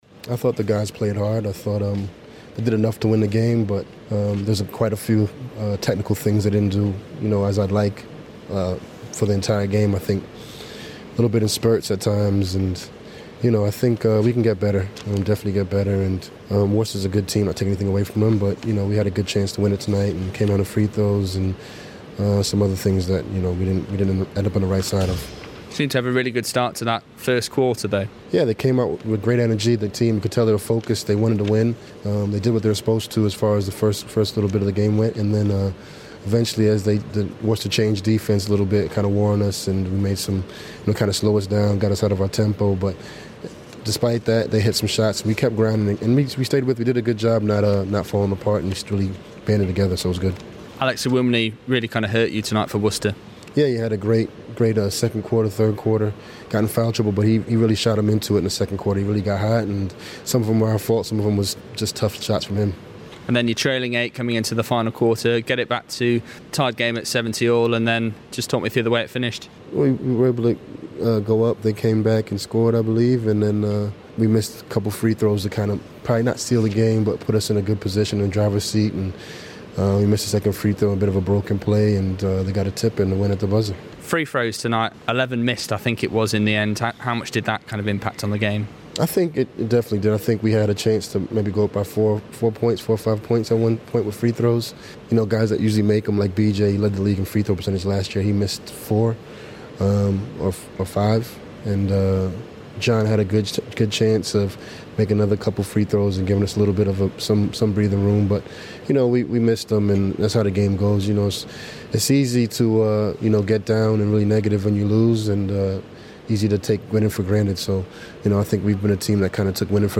INTERVIEW
Speaking to BBC Radio Sheffield's